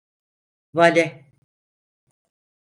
vale a 🐌 Oznaczający Koncepcje Synonimy Tłumaczenia Notes Extra tools (Angielski) valet jack Częstotliwość C2 Wymawiane jako (IPA) /ˈva.le/ Etymologia (Angielski) Borrowed from French valet.